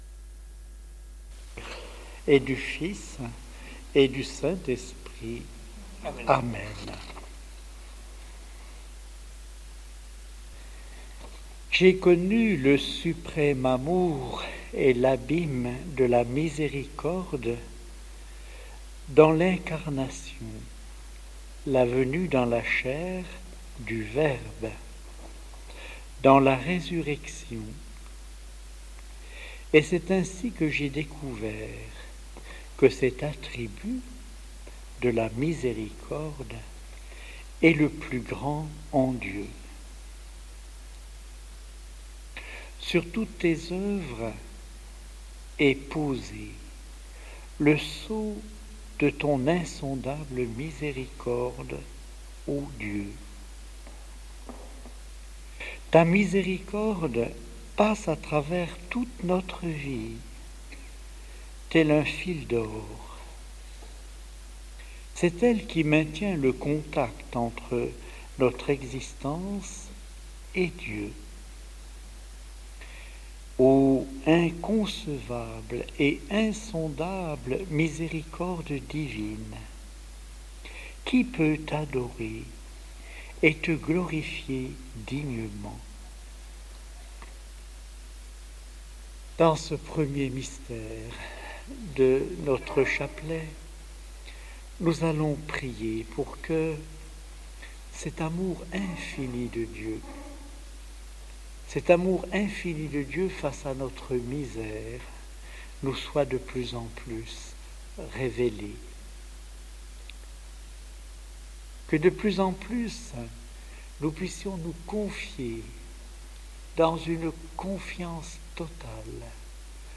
Chapelet prié et médité avec Ste Faustine - Prédications